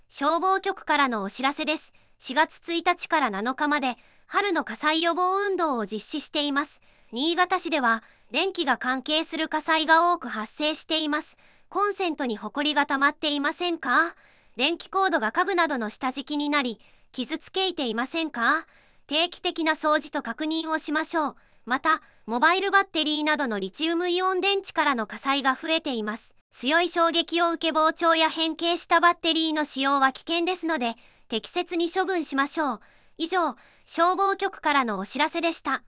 本音声データは、当運動期間中の館内放送のための音声データとなります。